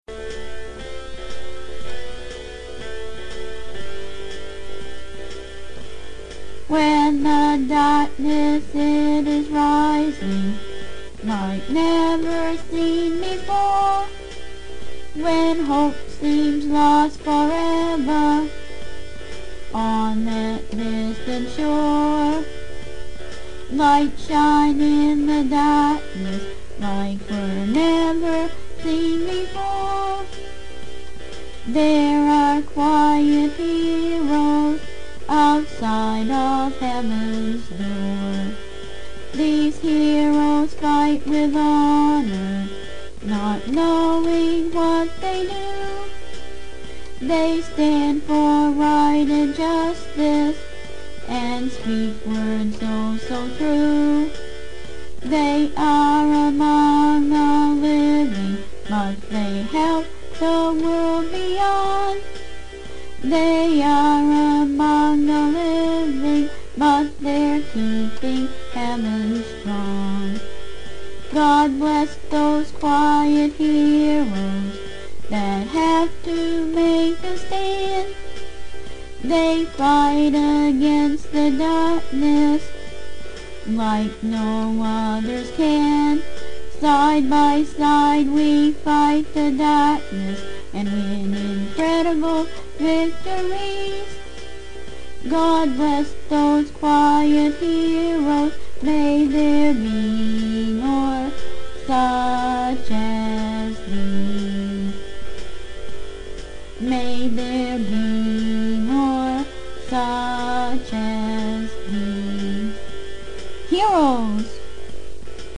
Sing This Song
And Accompany Herself On Her Keyboard.